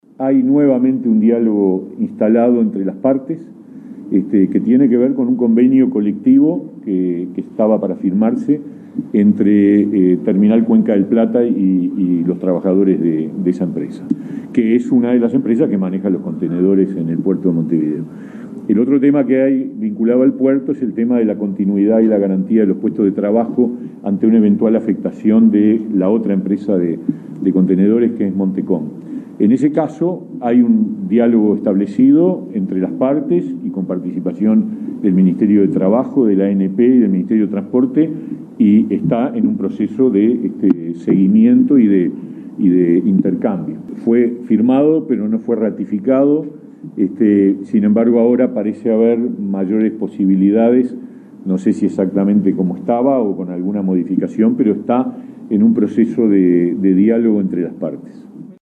Por su parte, el ministro de Trabajo, Pablo Mieres, reafirmó en conferencia de prensa consignada por Telenoche que «hay nuevamente un diálogo entre las partes» en el marco de este conflicto, y señaló que «parece haber mayores posibilidades de que sea ratificado el convenio».